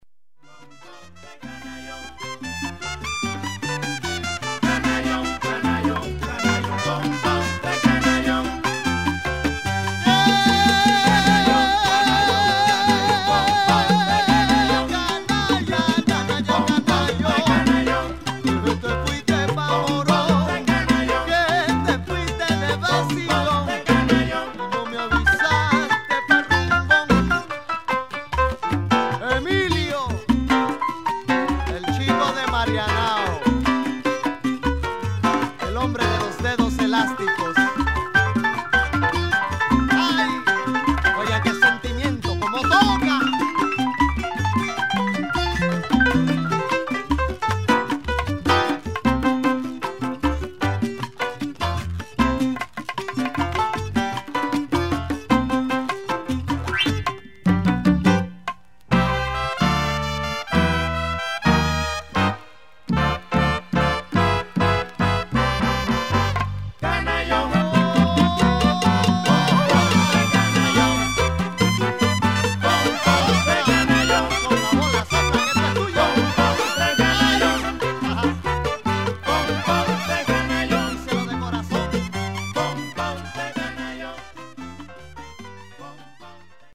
80S Cuban Son